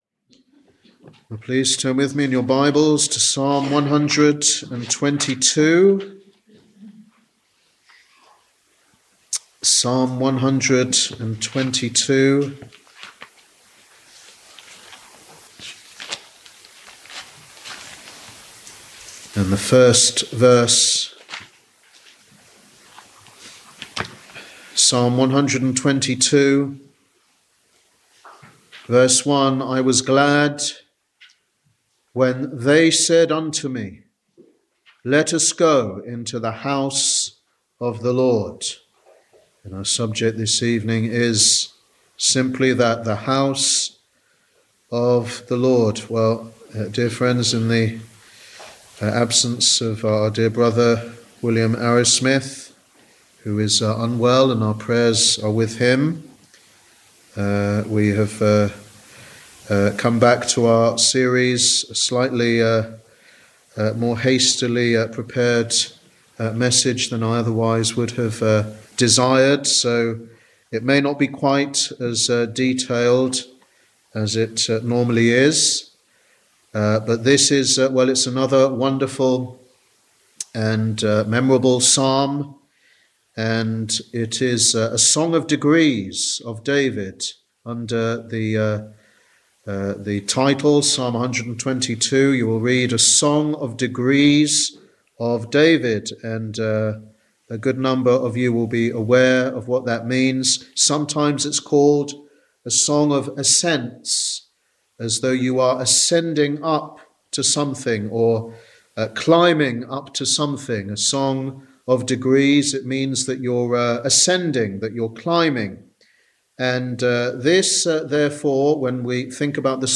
Wednesday Bible Study